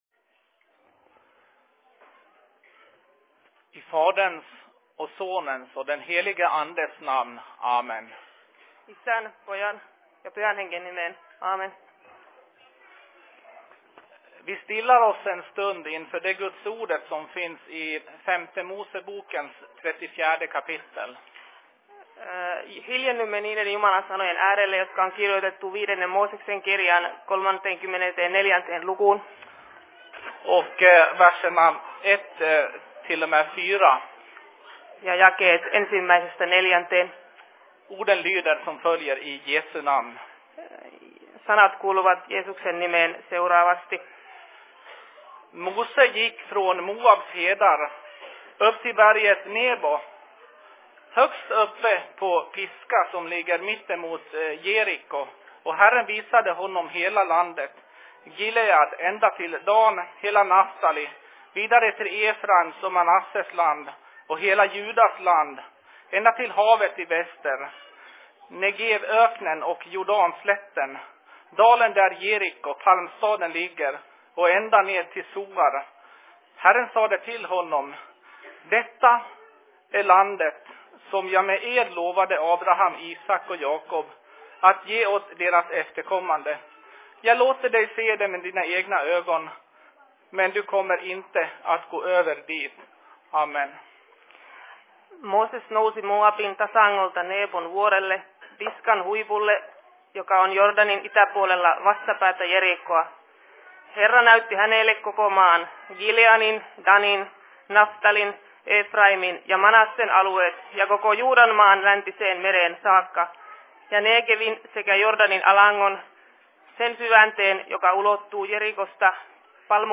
Se Fi Predikan I Dalarnas Fridsförening 15.02.2015
Plats: SFC Dalarna
Simultantolkat Svenska, Finska